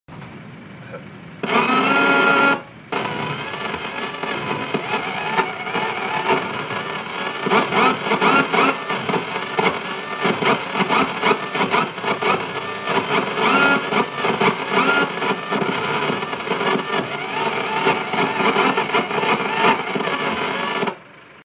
دانلود صدای برف پاک کن 1 از ساعد نیوز با لینک مستقیم و کیفیت بالا
جلوه های صوتی
برچسب: دانلود آهنگ های افکت صوتی حمل و نقل دانلود آلبوم صدای برف پاک کن ماشین از افکت صوتی حمل و نقل